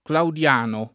[ klaud L# no ]